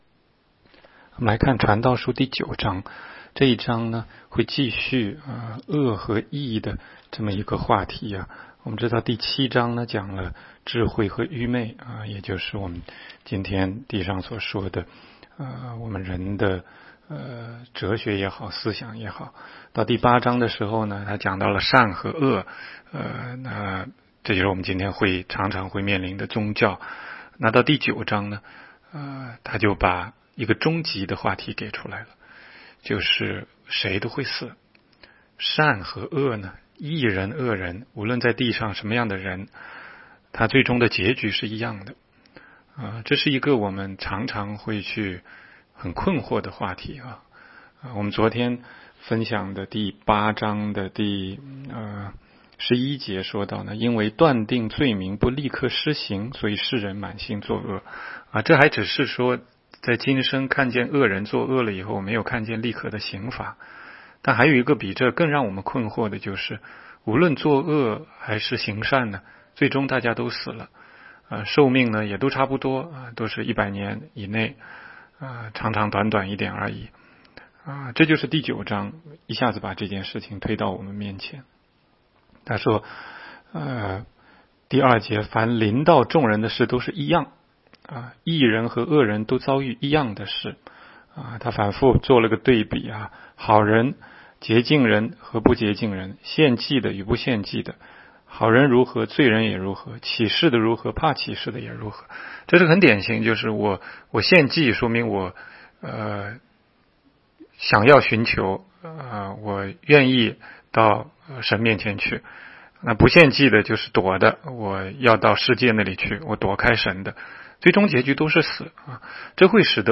16街讲道录音 - 每日读经 -《 传道书》9章
每日读经